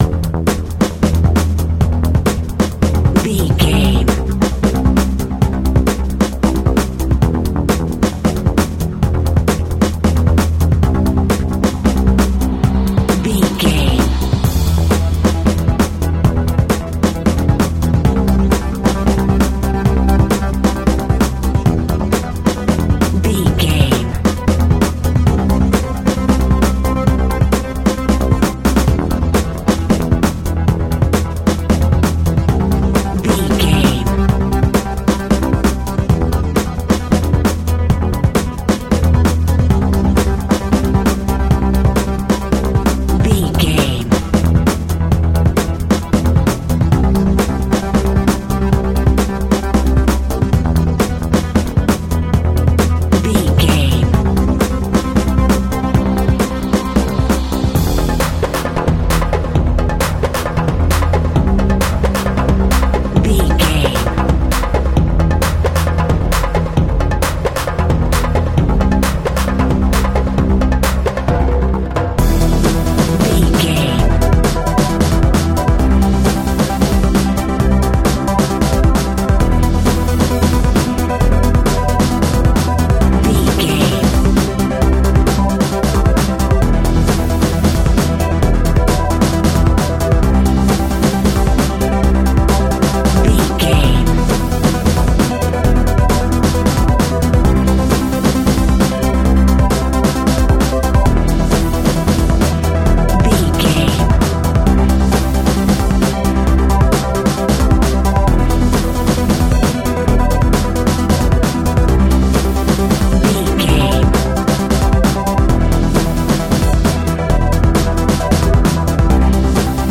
Aeolian/Minor
frantic
energetic
dark
hypnotic
industrial
drum machine
tabla
techno music
synth bass
synth pad